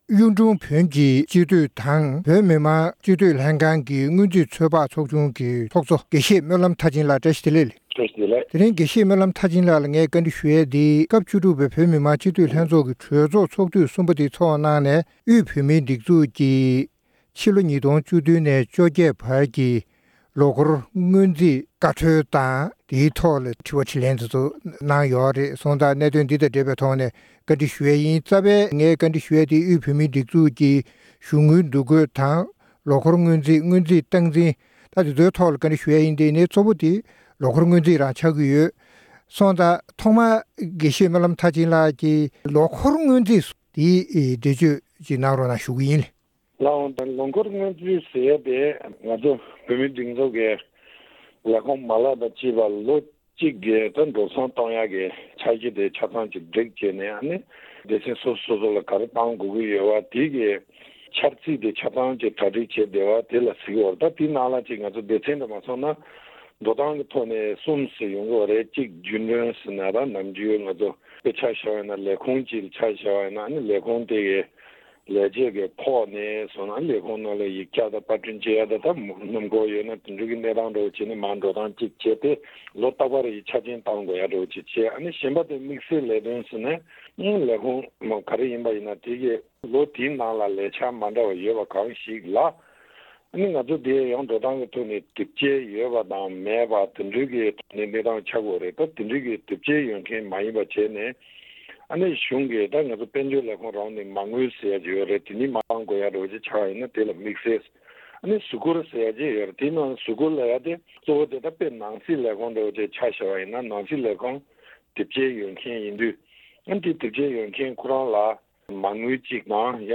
དབུས་བོད་མིའི་སྒྲིག་འཛུགས་ཀྱི་གཞུང་དངུལ་འདུ་འགོད་དང་། ལོ་འཁོར་སྔོན་རྩིས་སོགས་ཀྱི་སྐོར་གླེང་མོལ།